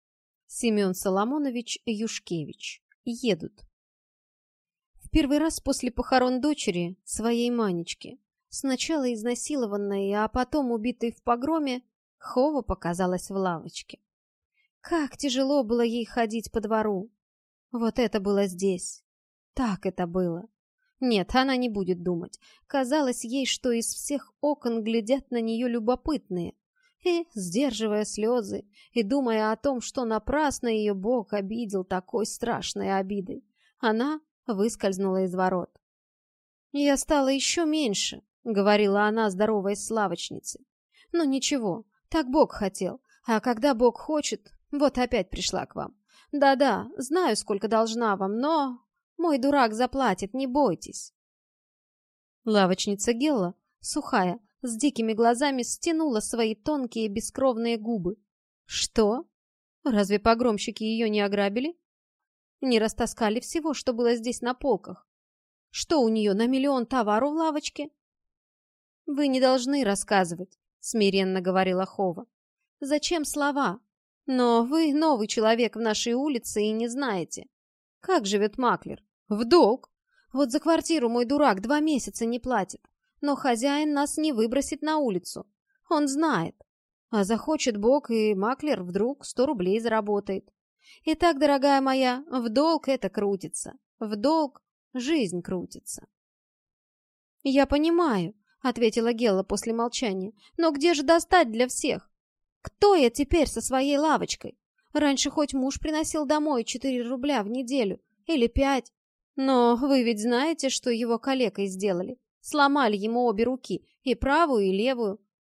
Aудиокнига
Прослушать и бесплатно скачать фрагмент аудиокниги